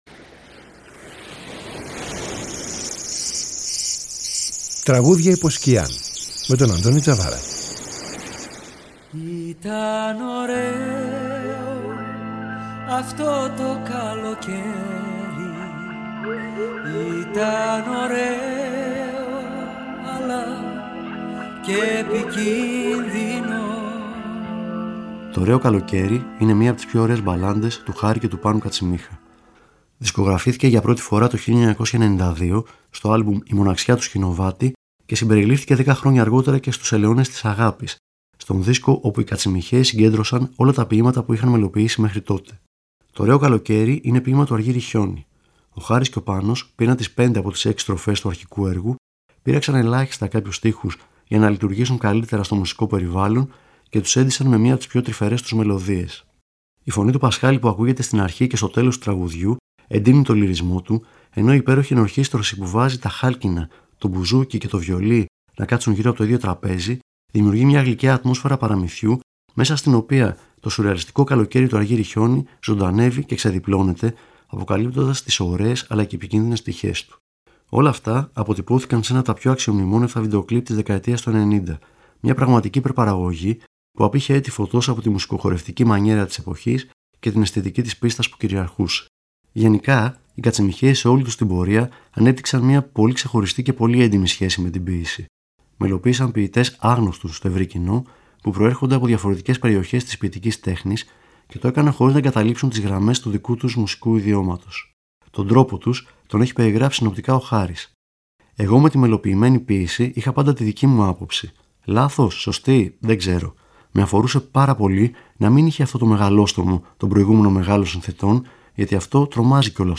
Η φωνή του Πασχάλη που ακούγεται στην αρχή και στο τέλος του τραγουδιού εντείνει τον λυρισμό του, ενώ η υπέροχη ενορχήστρωση που βάζει τα χάλκινα, το μπουζούκι και το βιολί να κάτσουν γύρω από το ίδιο τραπέζι, δημιουργεί μια γλυκιά ατμόσφαιρα παραμυθιού, μέσα στην οποία το σουρεαλιστικό καλοκαίρι του Αργύρη Χιόνη ζωντανεύει και ξεδιπλώνεται, αποκαλύπτωντας τις ωραίες αλλά και επικίνδυνες πτυχές του.